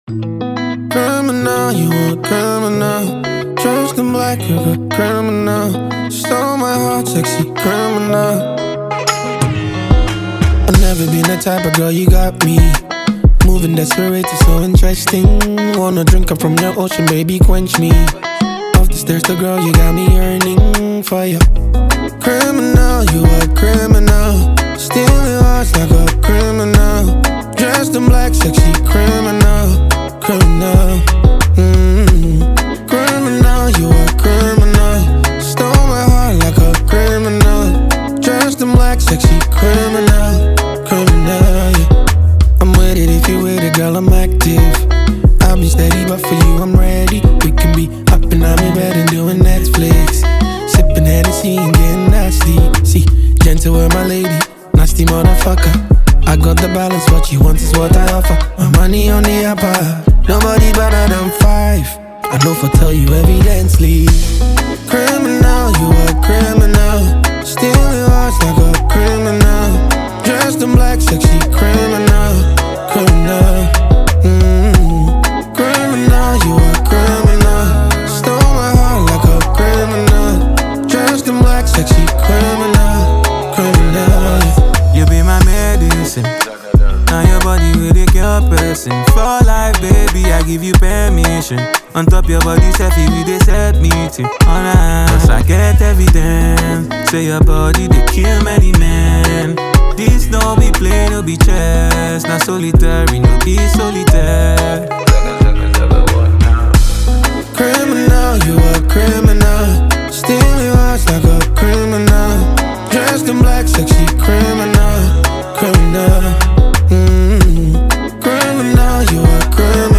Afropop
single